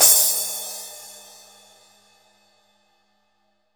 Index of /90_sSampleCDs/Best Service - Real Mega Drums VOL-1/Partition G/CYMBALS
14 CRASH.wav